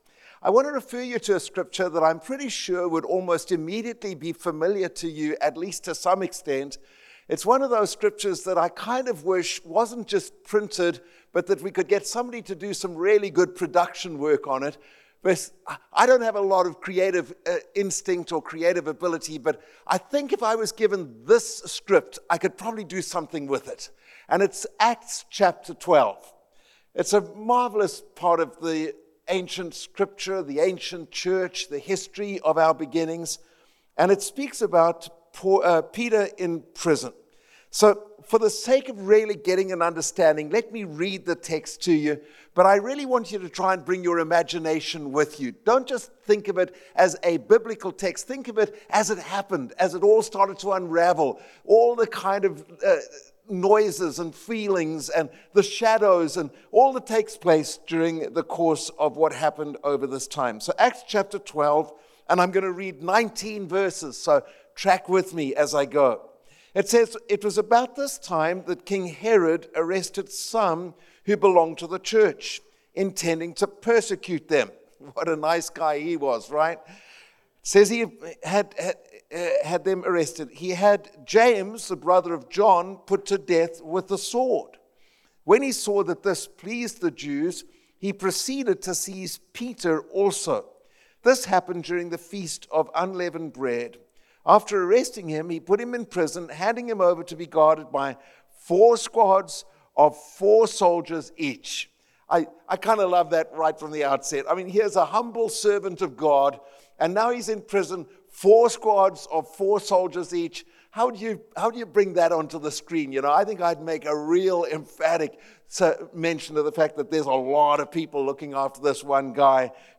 Family Camp 2025